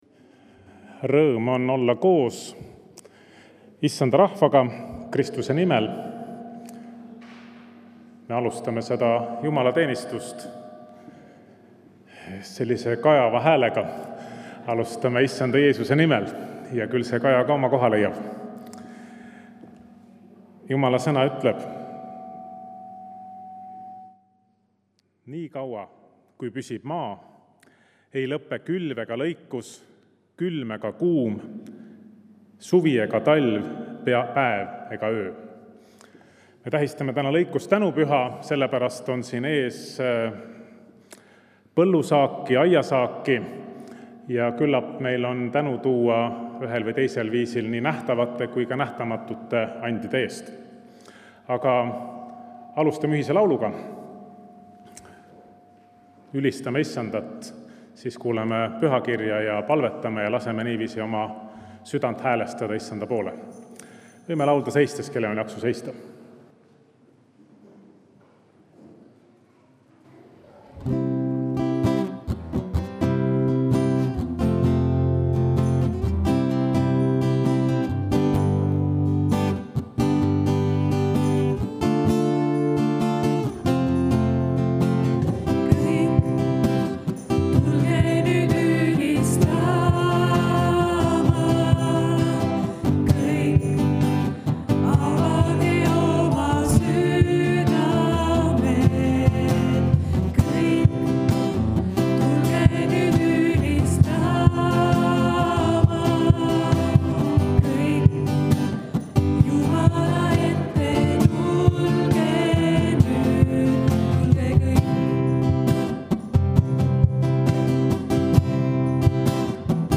Kõik jutlused